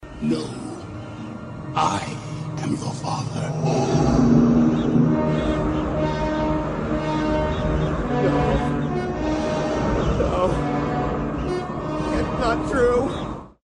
Tags: meme sound; meme effects; youtube sound effects;